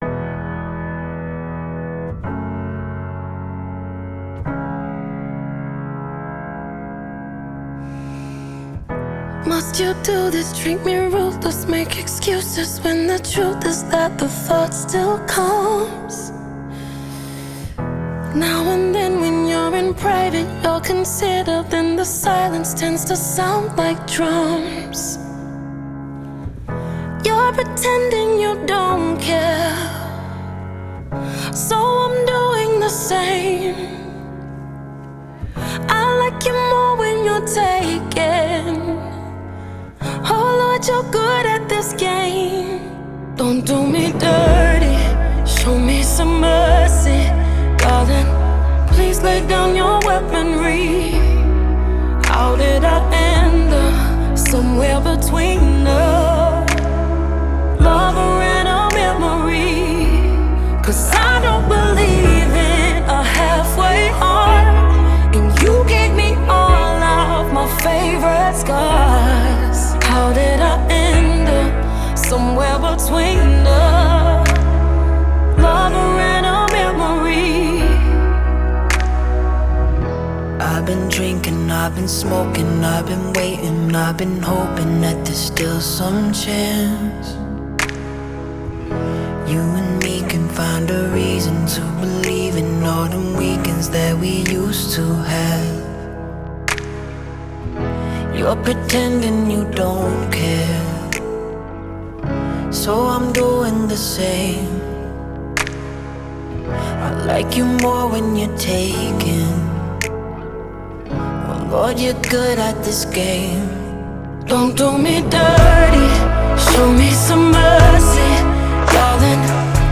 мощная поп-баллада